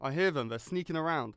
Voice Lines / Combat Dialogue